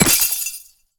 ice_spell_impact_shatter_02.wav